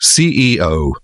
CEO se pronuncia da seguinte forma: (ci-i-ou), mais pausadamente, como se tivesse um “i” no meio.
ceo-pronuncia.mp3